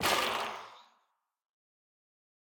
Minecraft Version Minecraft Version latest Latest Release | Latest Snapshot latest / assets / minecraft / sounds / block / sculk_shrieker / break3.ogg Compare With Compare With Latest Release | Latest Snapshot